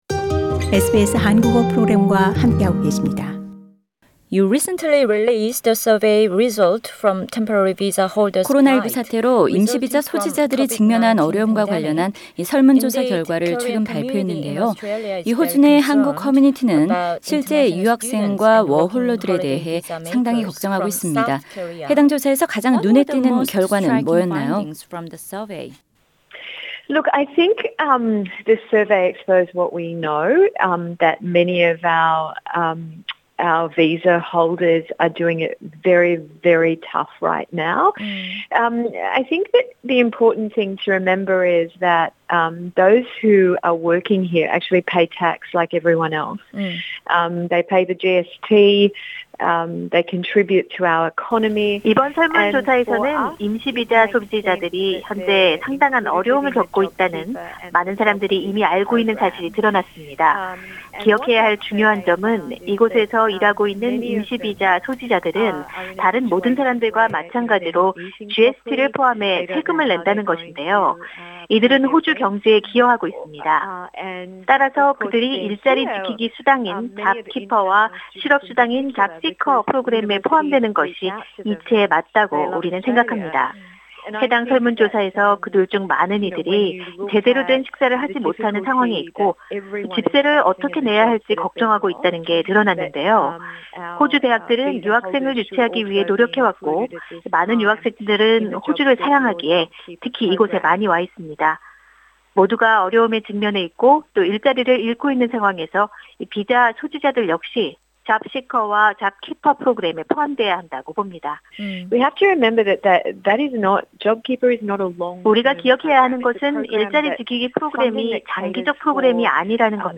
[단독 대담] 조디 맥케이 NSW 노동당 당수 "벼랑 끝 임시비자 소지자들, 정부가 지원해야"